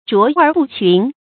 卓尔不群发音
成语注音 ㄓㄨㄛˊ ㄦˇ ㄅㄨˋ ㄑㄩㄣˊ